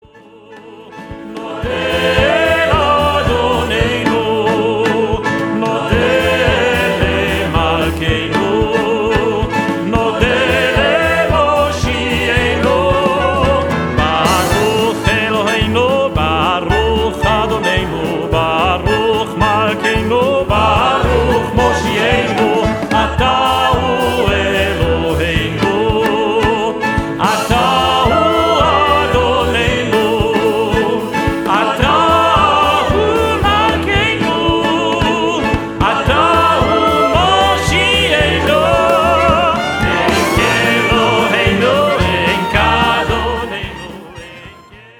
• Shabbat Evening/Morning